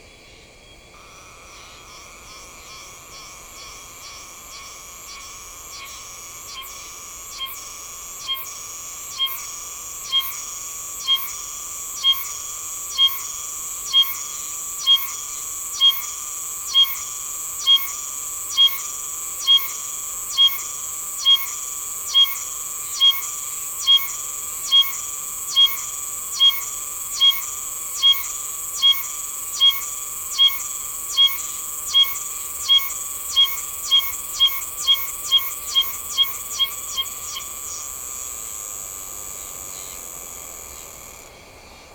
オオシマゼミの鳴き声
カン！カン！カン！
なんとなくもの悲しいような鳴き声ですね。沖縄諸島、奄美群島に分布するツクツクホウシの仲間です。
ooshimazemi-call.mp3